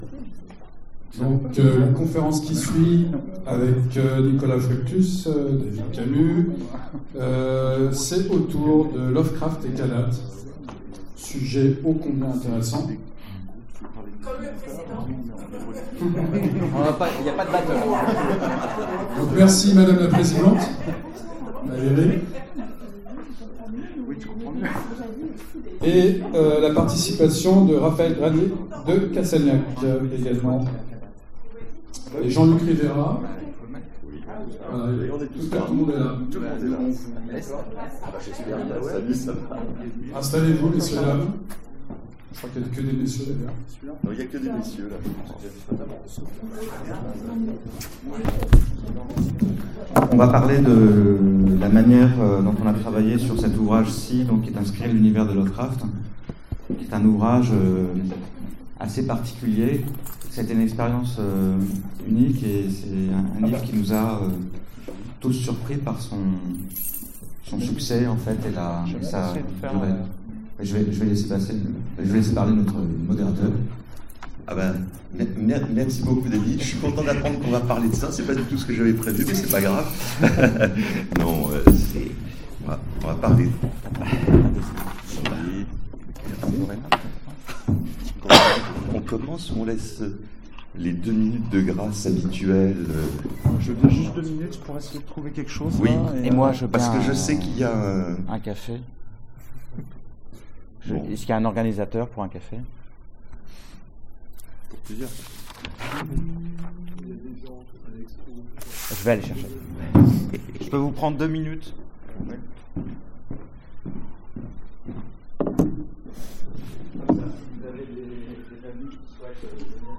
FMI 2015 : Conférence sur le Livre Kadath